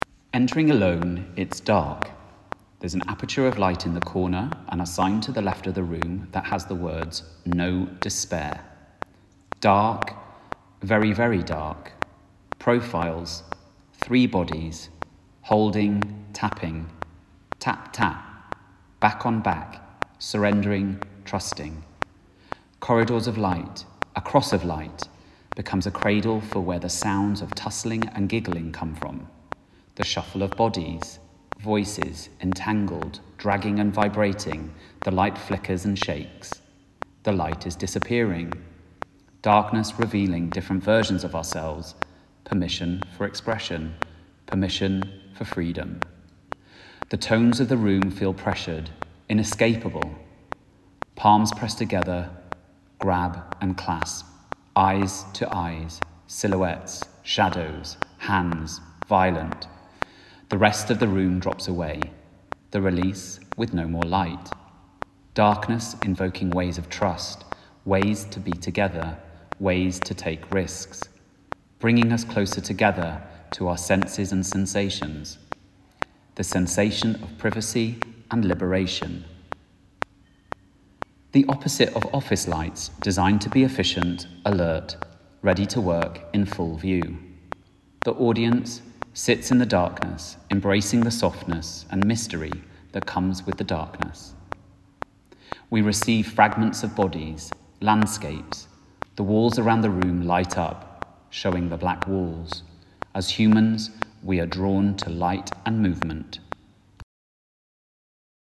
en Study - audio description
en Audiodescription